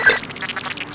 command_on.wav